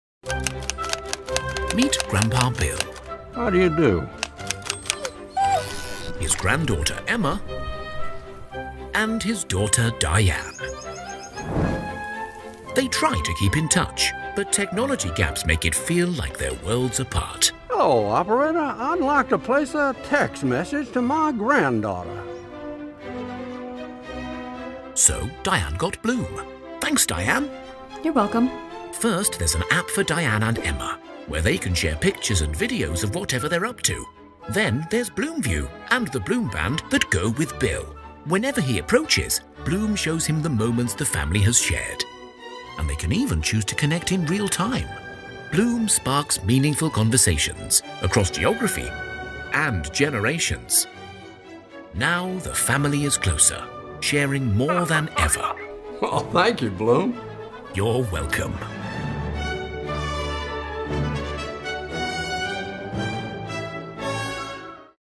Male
English (British)
Adult (30-50), Older Sound (50+)
Warm, confident, authoritative and reassuring RP with over twenty years experience and a global client base.
Television Spots